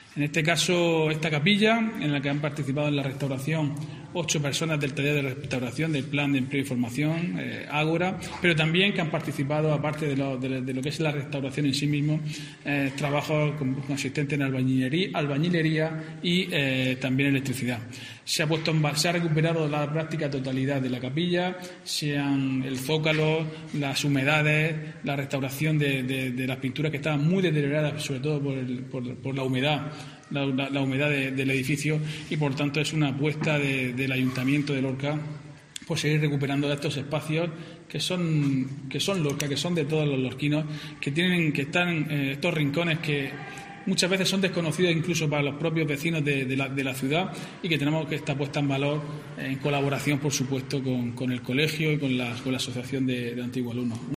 Diego José Mateos, alcalde de Lorca